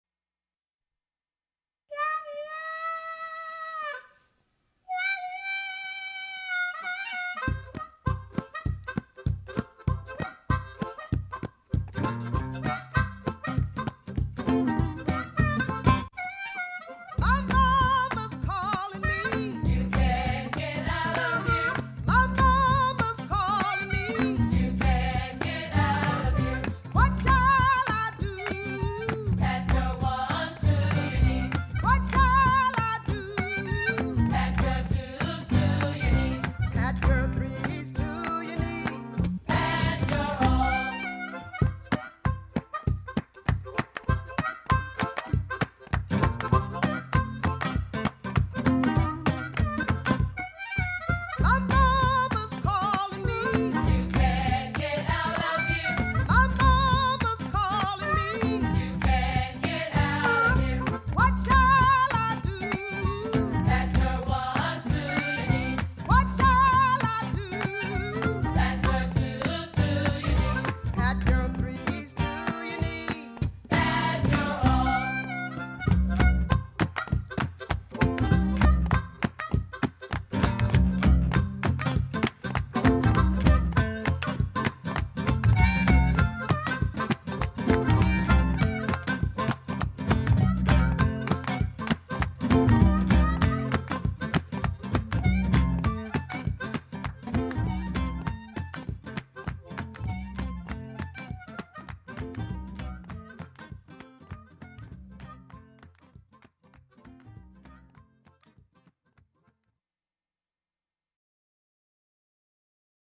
This is a song that is sung while playing a call and response game in a circle.
This song provides a simple example of the ‘call and response’ technique, which is an exchange between a leader (soloist) and the group (chorus).